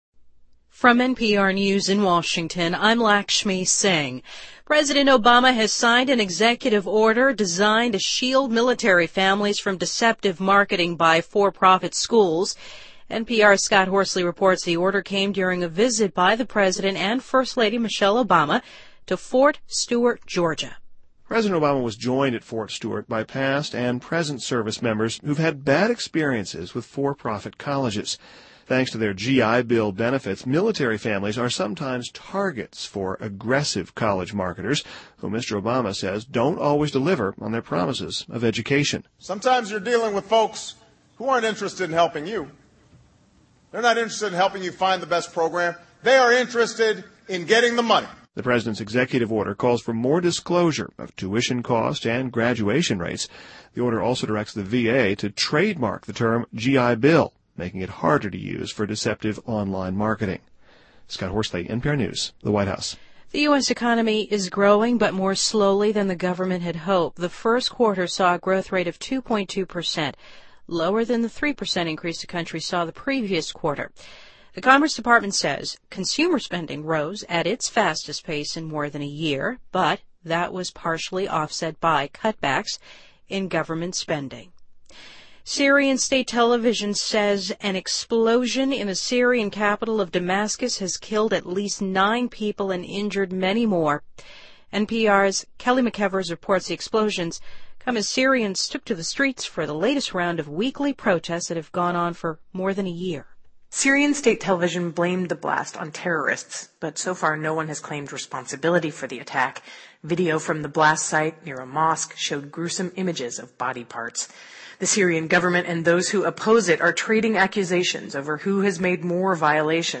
NPR News,丹麦警方逮捕3名涉嫌策划发动恐怖袭击的嫌疑人